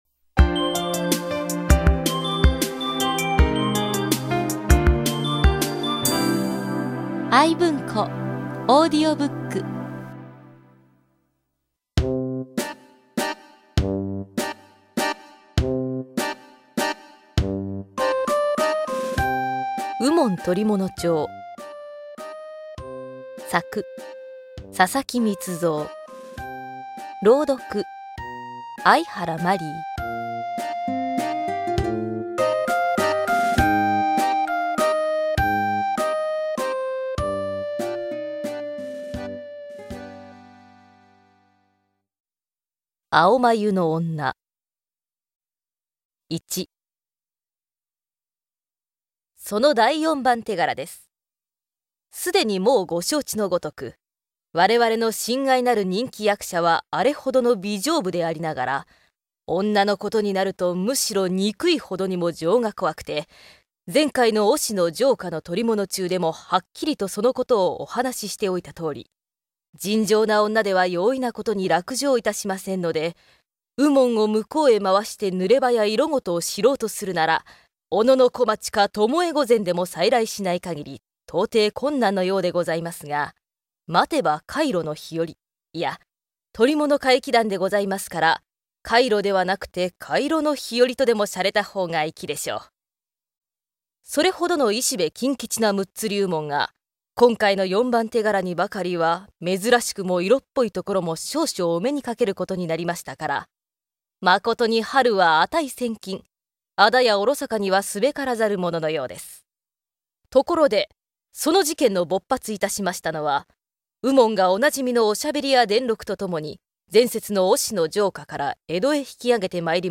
[オーディオブック] 右門捕物帖四「青眉の女」
戦前、嵐寛寿郎主演の映画で一世を風靡した江戸時代を舞台にした推理物「むっつり右門」が、好評にお応えして朗読で再登場です！